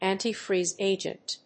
antifreeze+agent.mp3